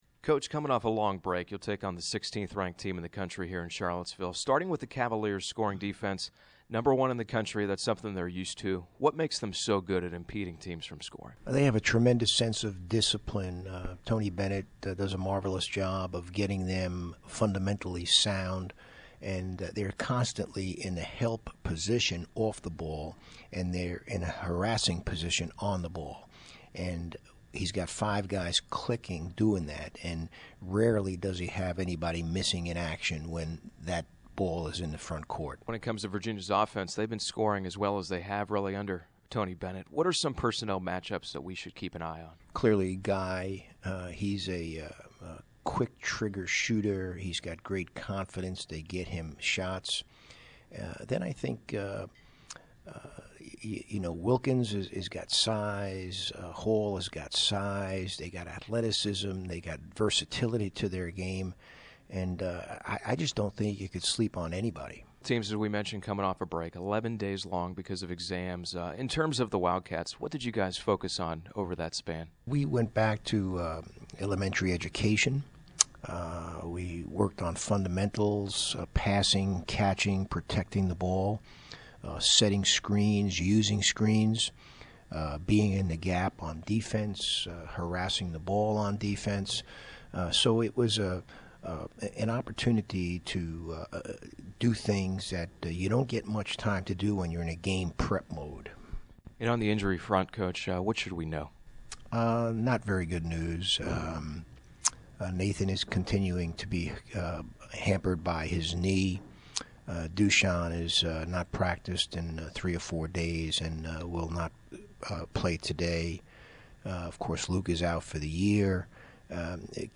Pregame Interview
Pregame UVA.mp3